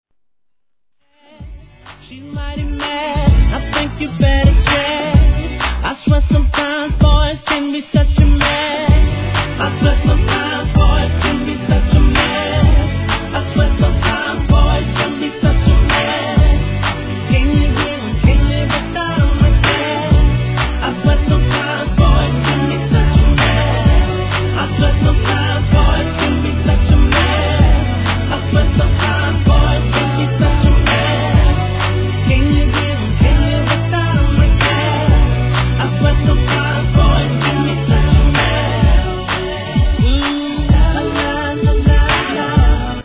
HIP HOP/R&B
129 BPM